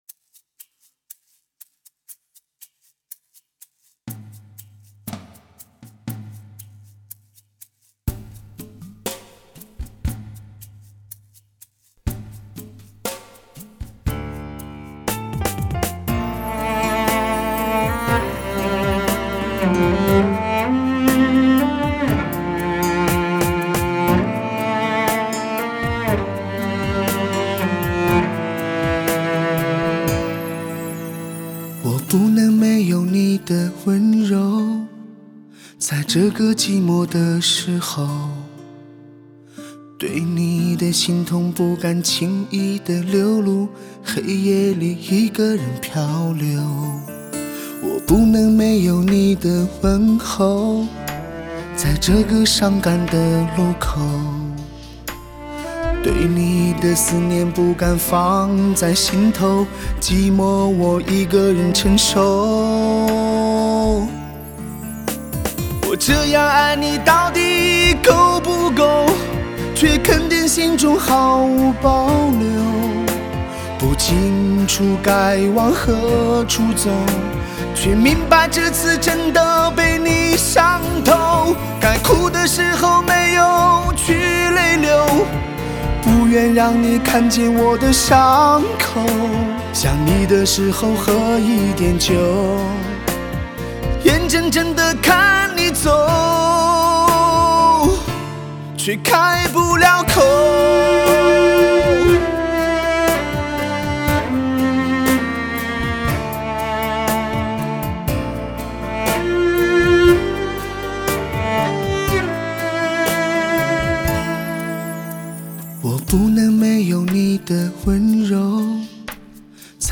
HIFI汽车音乐新典范。
发烧的音效，令你在驾车途中感受音乐的魅力与震撼。